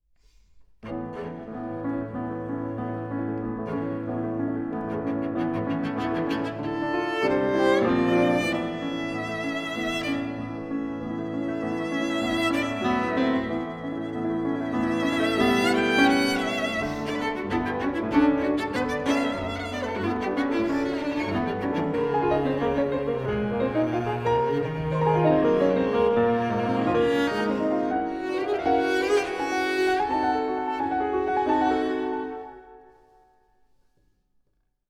au Conservatoire de Paris
violoncelle
piano